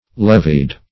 Levy \Lev"y\, v. t. [imp. & p. p. Levied (l[e^]v"[i^]d); p.